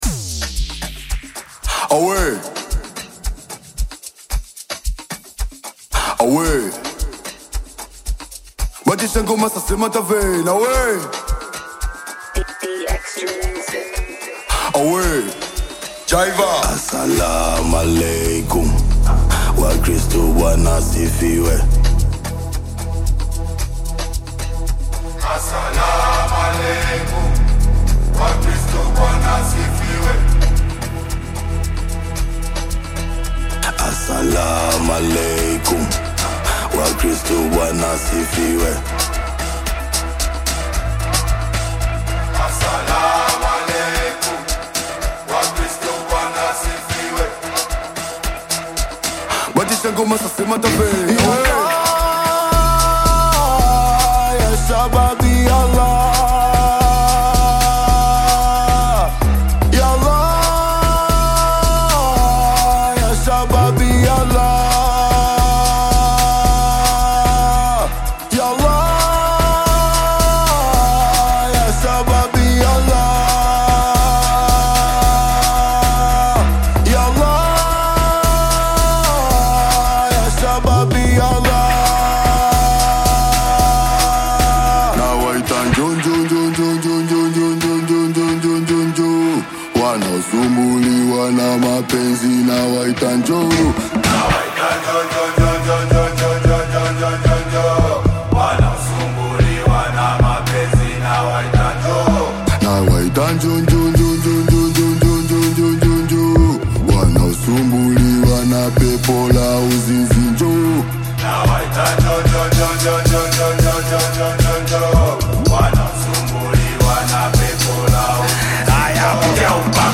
infectious Amapiano/Bongo Flava single
smooth vocals, uplifting rhythms
Genre: Amapiano